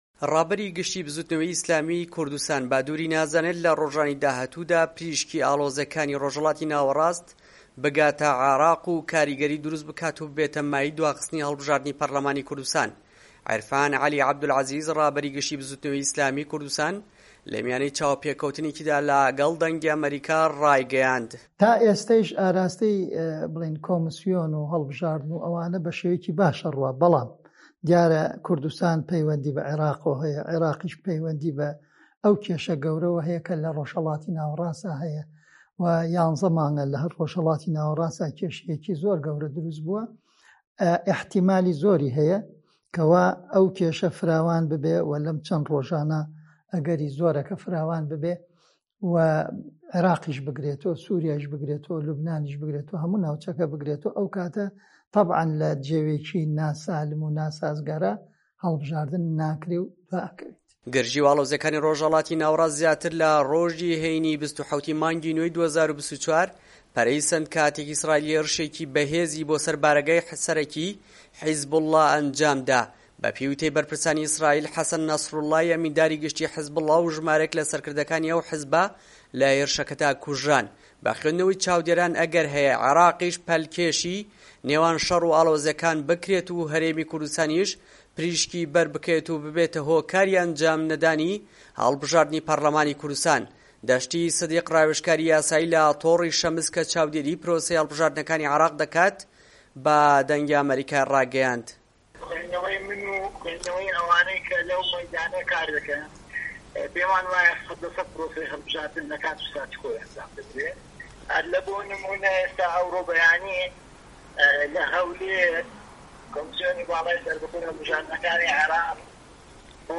چاوپێکەوتنێکیدا